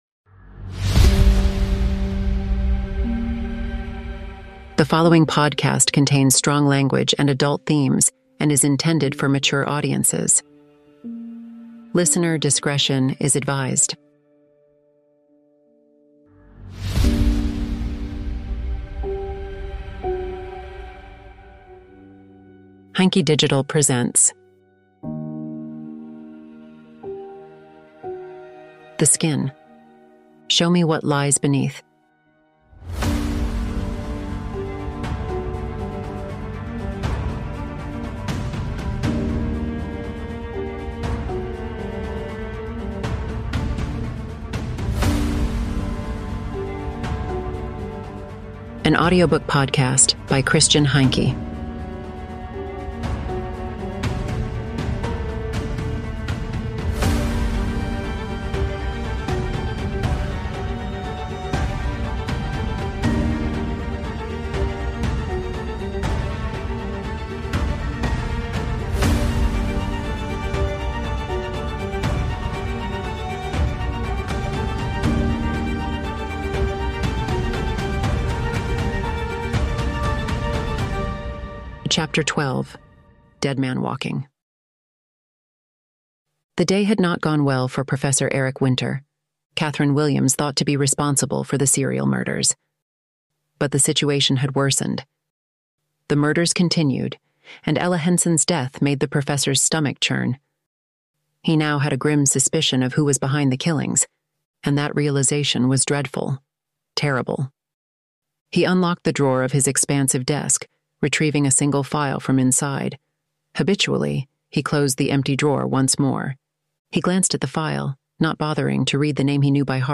Thanks to the latest advances in artificial intelligence, I am now able to have my texts edited and translated well enough to bring them to life using a variety of artificial voices.